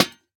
Minecraft Version Minecraft Version snapshot Latest Release | Latest Snapshot snapshot / assets / minecraft / sounds / block / lantern / break3.ogg Compare With Compare With Latest Release | Latest Snapshot
break3.ogg